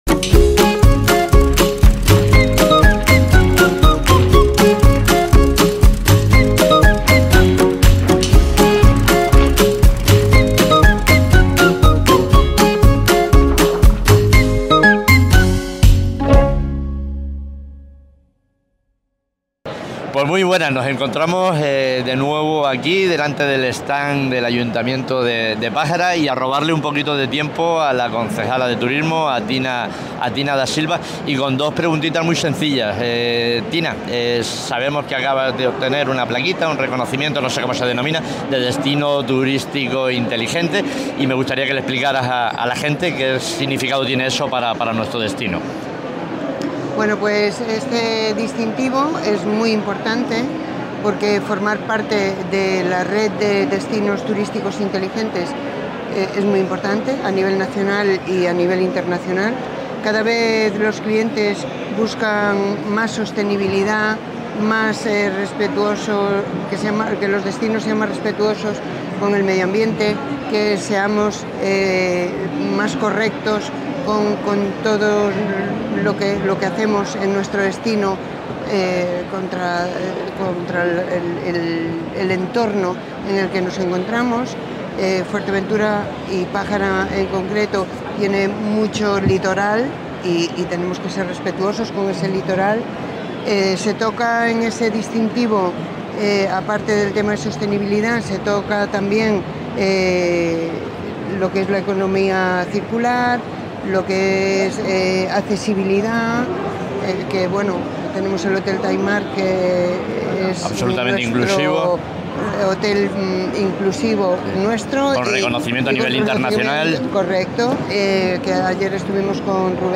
Fitur 2024: Entrevista a Tina Da Silva
Entrevistamos a la concejala de Turismo del municipio de Pájara, Tina Da Silva, en Fitur 2024.